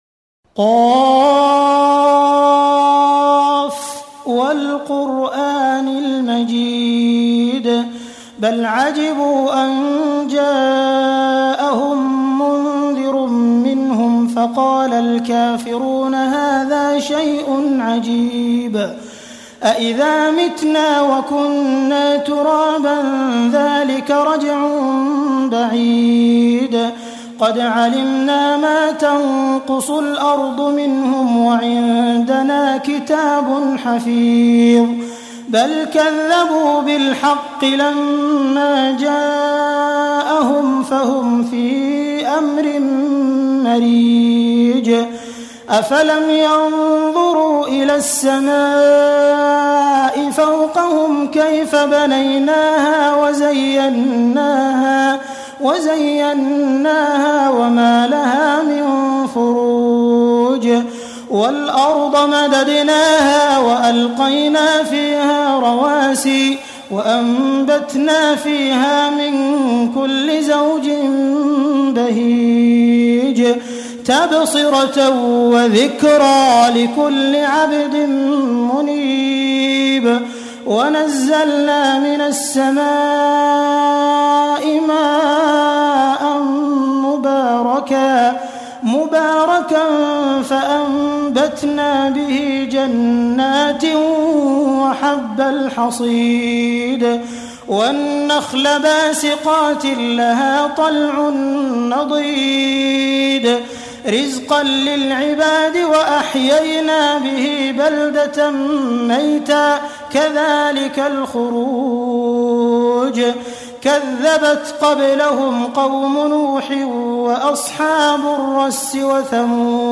Surah Qaf – Quran Talawat by Abdul Rahman Al-Sudais: Download MP3
Arabic ﻋﺭﺑﻰ , Complete Quran Talawat (Recitation)